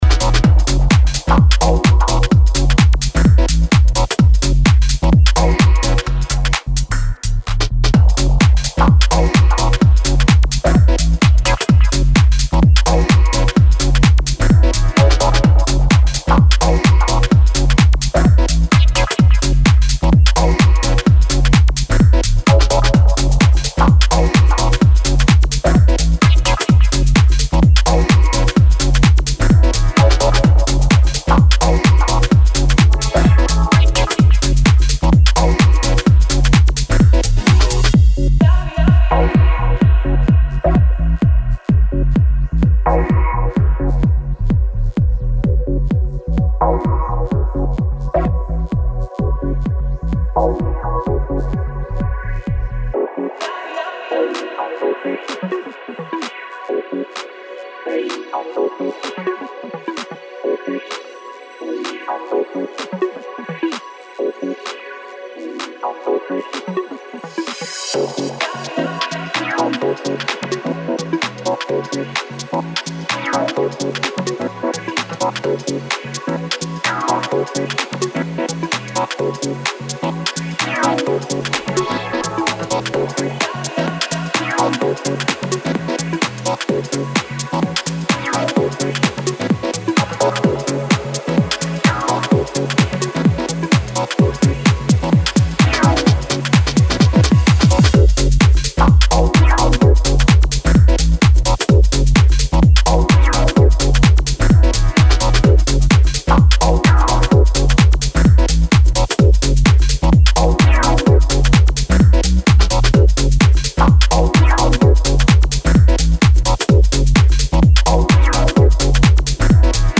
energetic & groove laden cuts